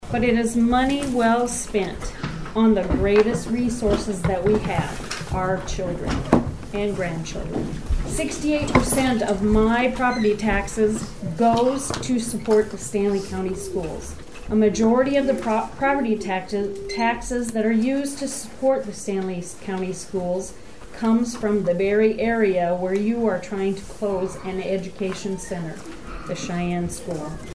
During the public comment portion, about a dozen residents– all of them opposed– addressed the possibility of closing the school.